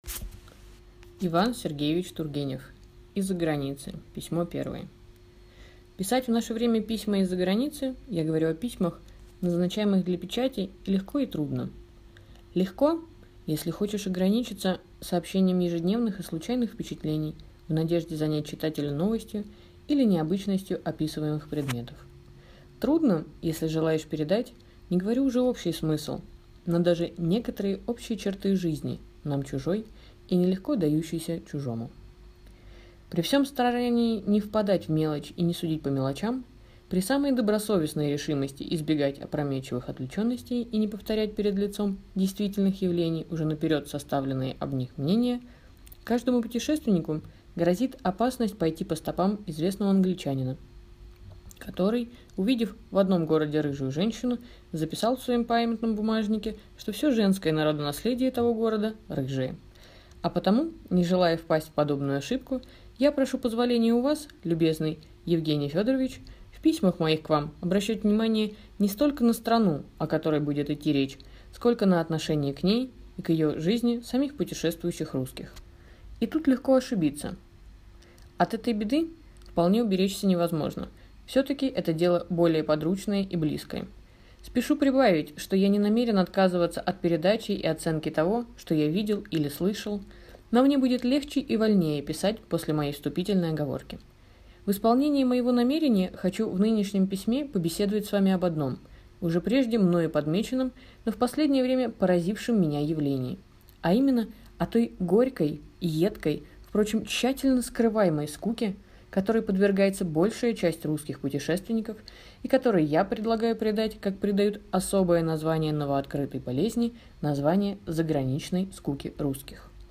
Аудиокнига Из-за границы | Библиотека аудиокниг